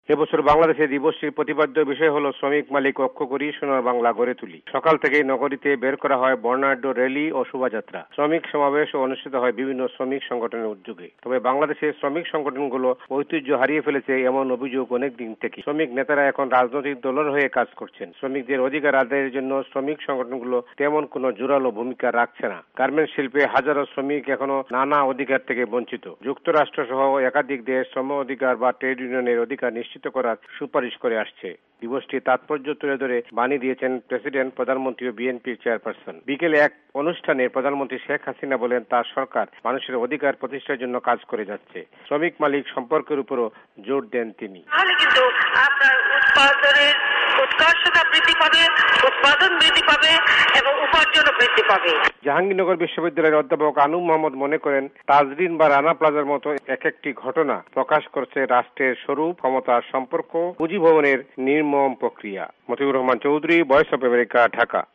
টেলিফোন বার্তা